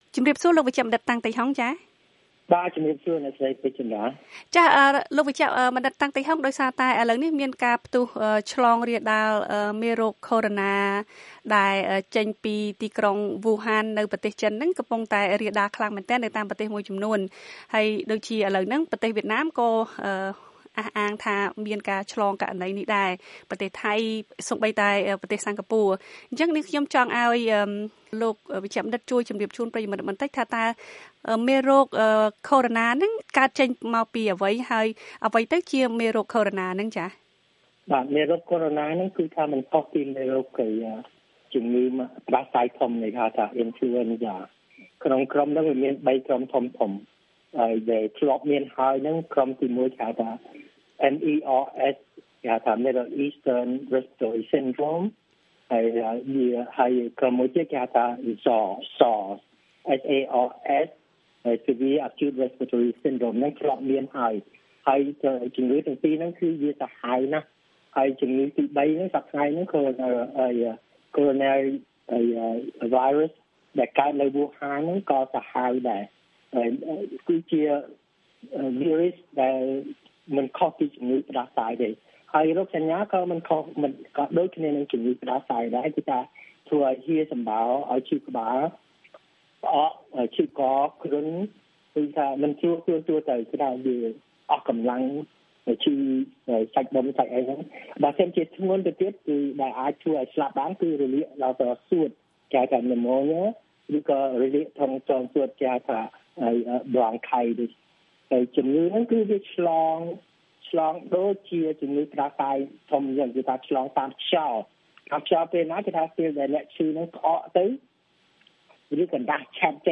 បទសម្ភាសន៍ VOA៖ ការគំរាមកំហែងនៃការផ្ទុះឆ្លងរាលដាលមេរោគ Coronavirus